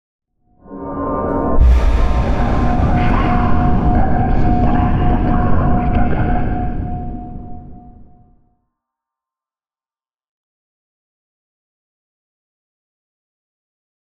divination-magic-sign-rune-intro-fade.ogg